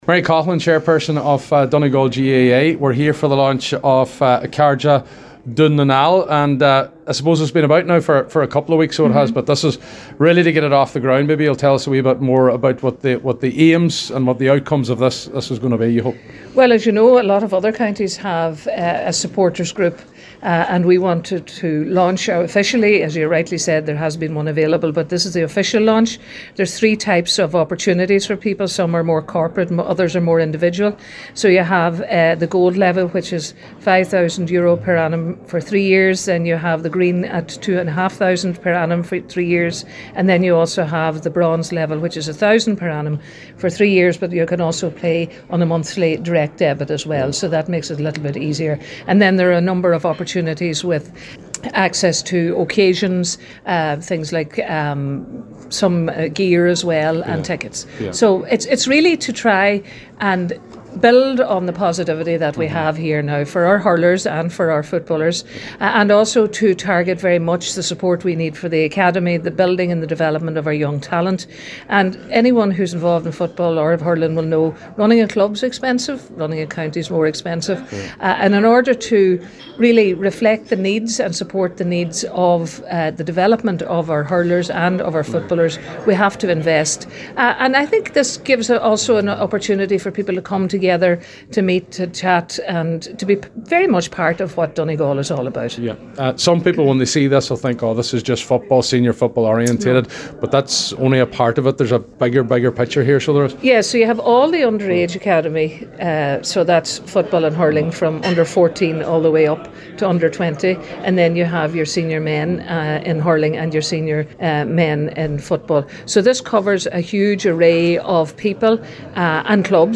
Donegal GAA chairperson Mary Coughlan at this evening’s launch…